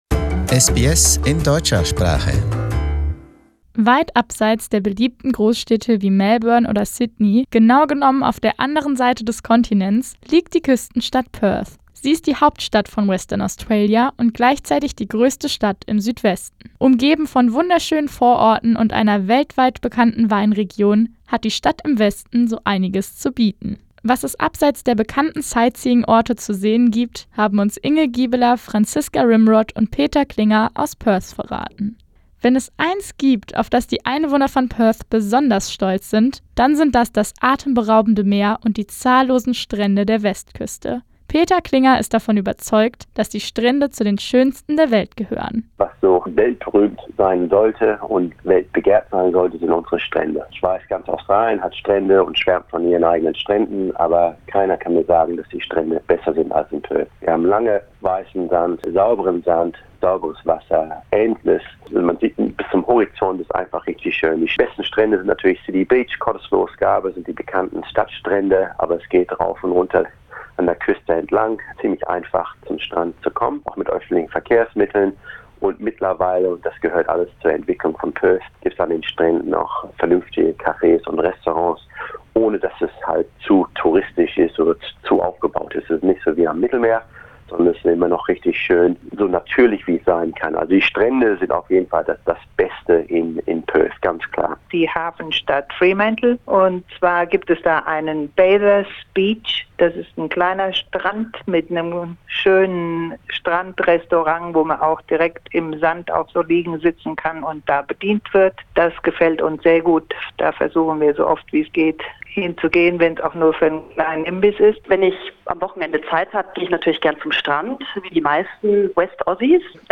Perth is located in Western Australia and surrounded by beautiful beaches and nature. We spoke to three Germans and asked them for their favorite hidden places in Perth.